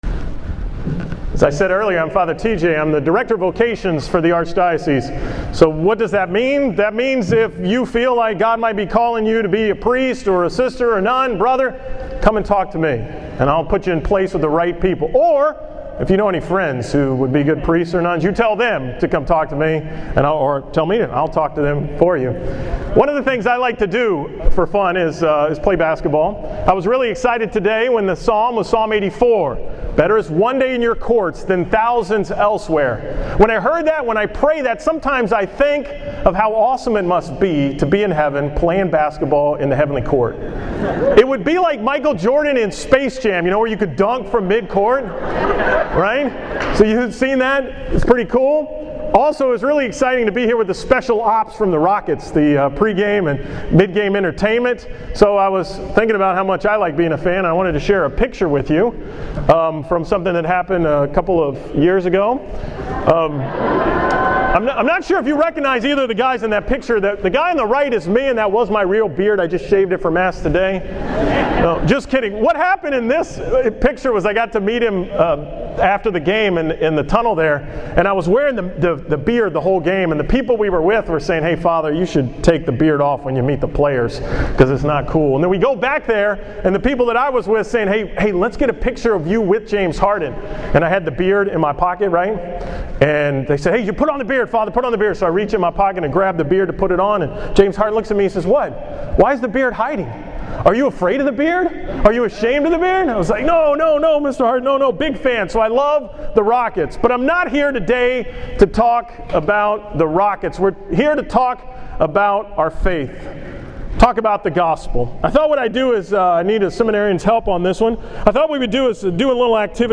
The Saturday Mass at the Archdiocesan Youth Conference.